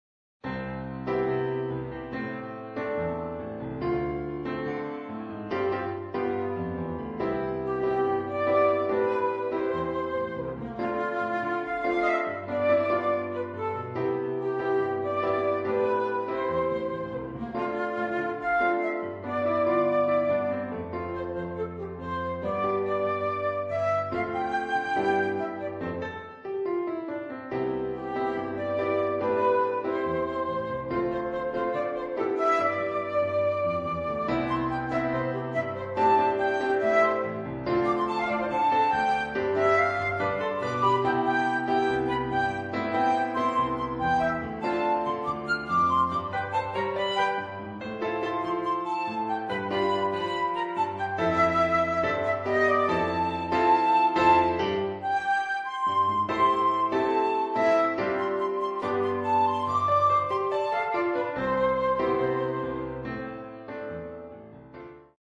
per flauto e pianoforte